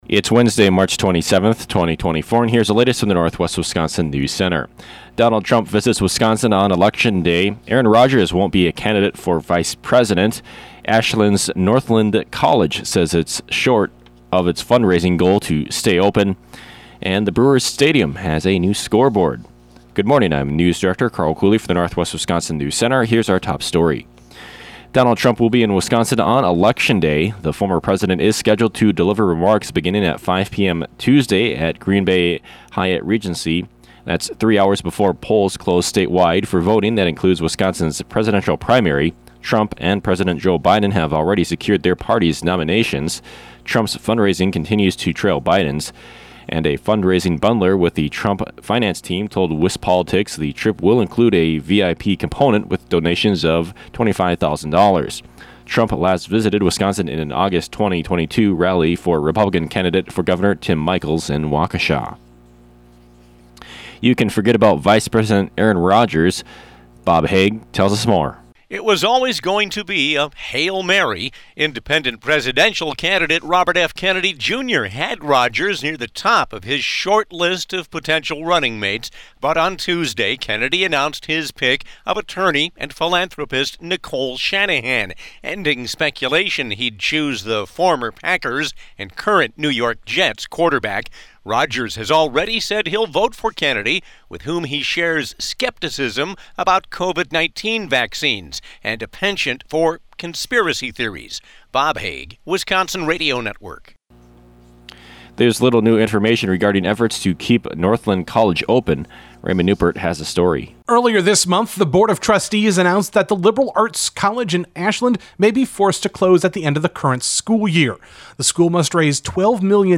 AM NEWSCAST – Wednesday, March 27, 2024